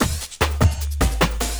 50 LOOP09 -R.wav